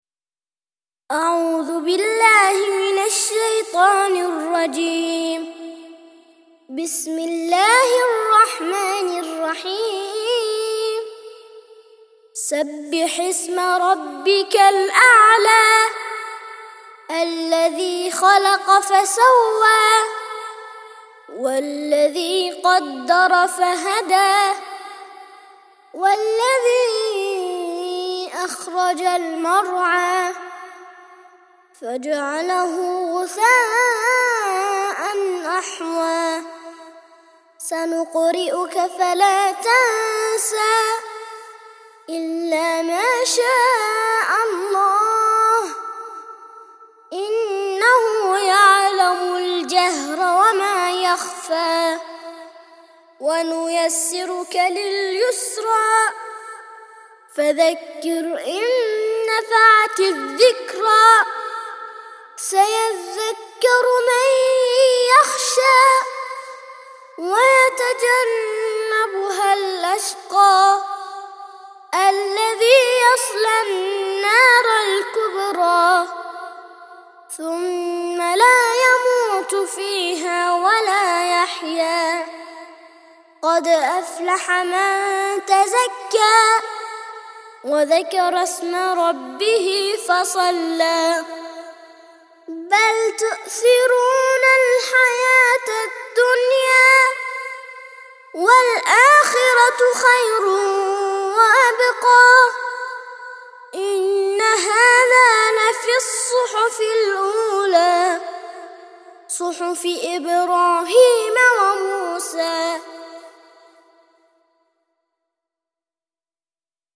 87- سورة الأعلى - ترتيل سورة الأعلى للأطفال لحفظ الملف في مجلد خاص اضغط بالزر الأيمن هنا ثم اختر (حفظ الهدف باسم - Save Target As) واختر المكان المناسب